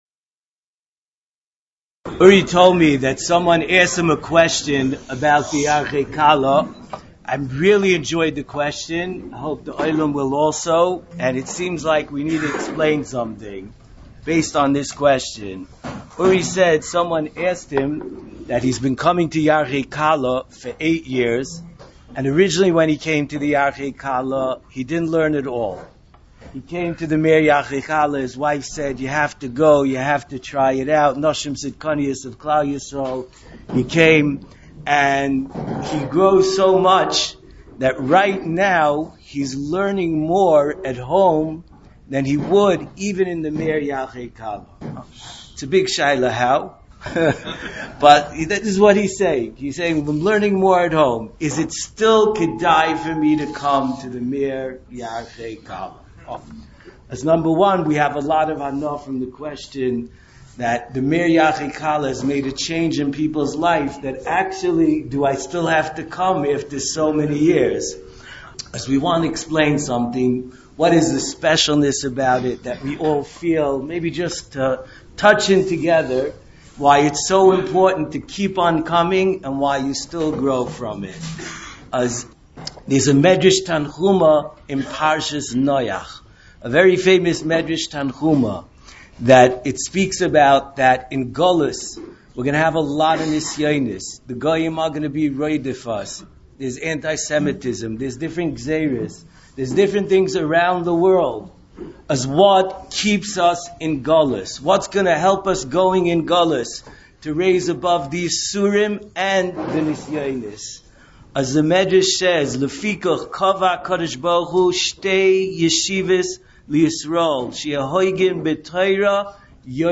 04 Yarchei Kallah 2025 - שיעור הקדמה - Sugya of שליח לדבר עבירה - Yeshivas Mir Yerushalayim
Shiurim